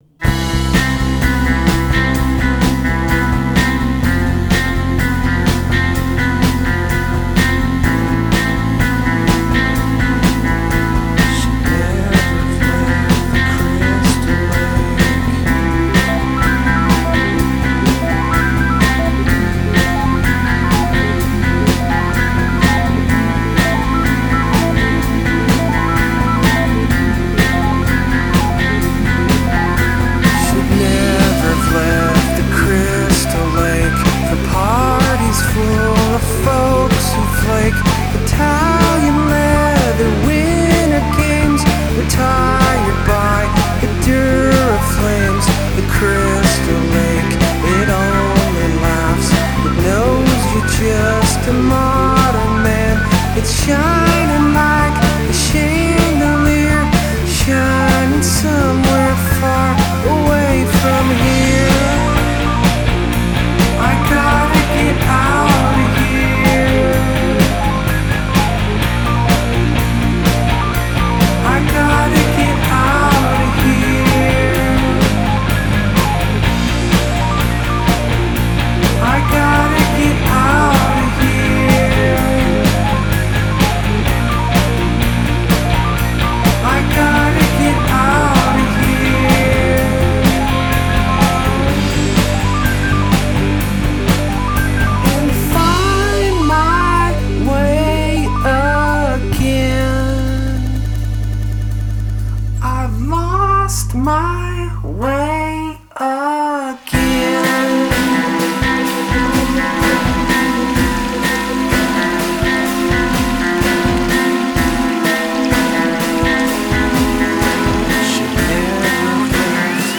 dreamy